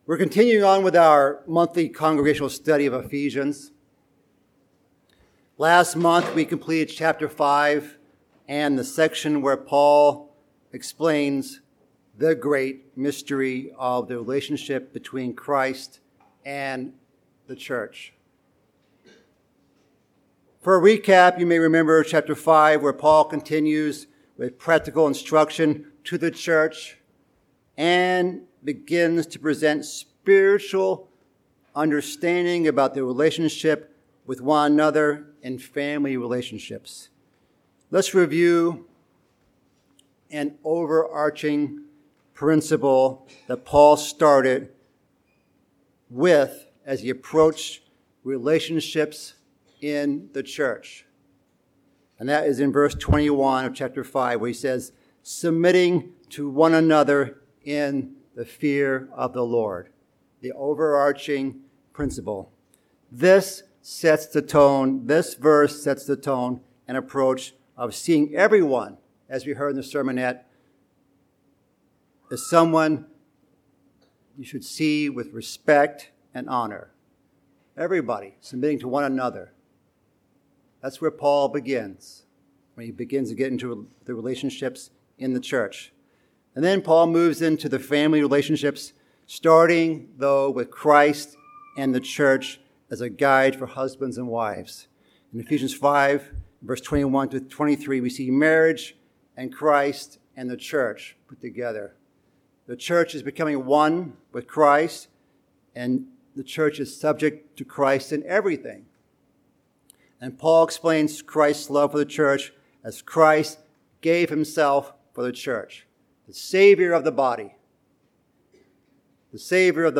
Bible Study: Ephesians